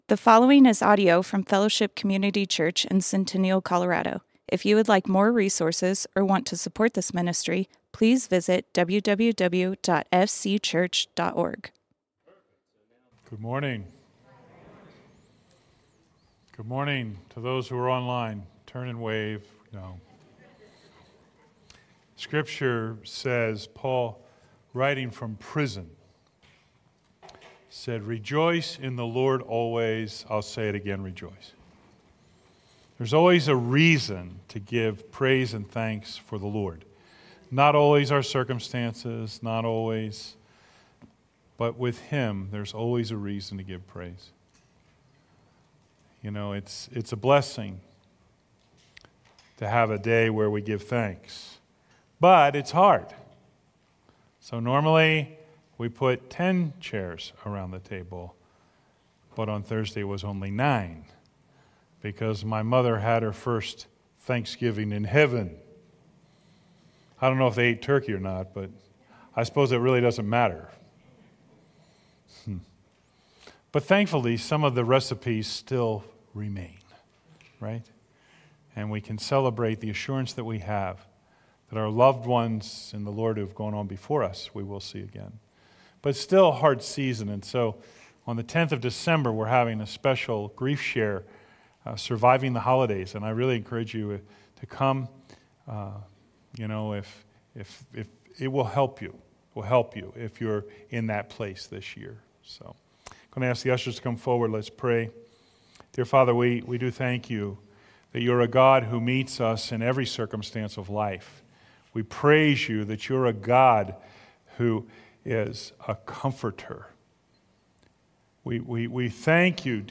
Fellowship Community Church - Sermons Son of the Most High Play Episode Pause Episode Mute/Unmute Episode Rewind 10 Seconds 1x Fast Forward 30 seconds 00:00 / 30:31 Subscribe Share RSS Feed Share Link Embed